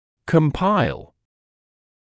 [kəm’paɪl][кэм’пайл]составлять (одно целое из составных часте, напр. книгу, статью, отчет)